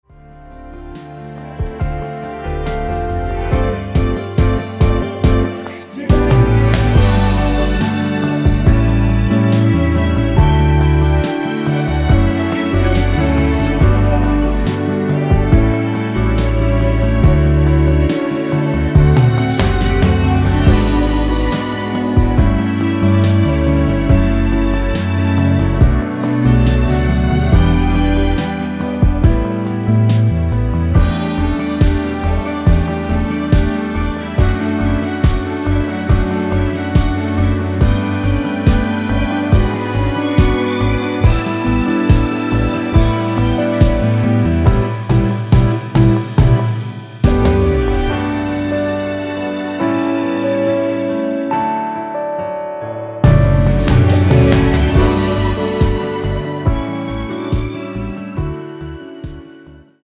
원키에서(-2)내린 코러스 포함된 MR입니다.
◈ 곡명 옆 (-1)은 반음 내림, (+1)은 반음 올림 입니다.
앞부분30초, 뒷부분30초씩 편집해서 올려 드리고 있습니다.